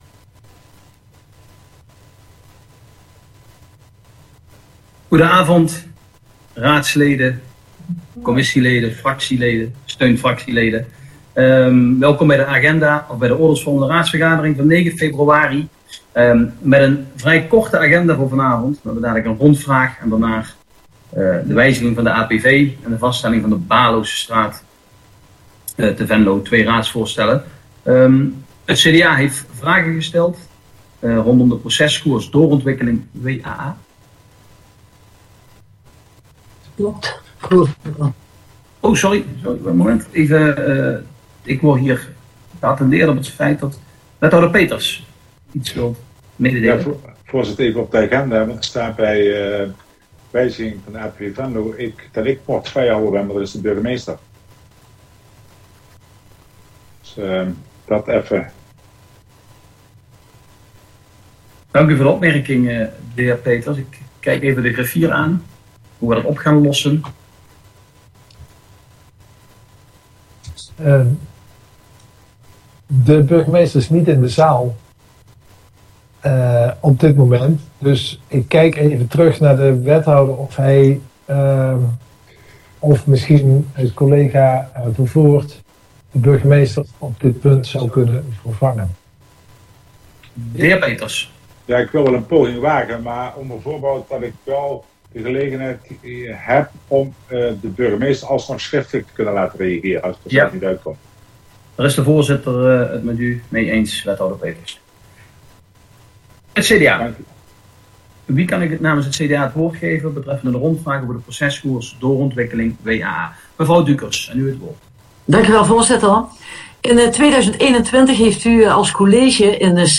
Oordeelsvormende raadsvergadering 09 februari 2022 19:00:00, Gemeente Venlo
Sessievoorzitter: Gerrit Schuurs Portefeuillehouder: wethouder Sjors Peeters
Locatie: Stadhuis Parterre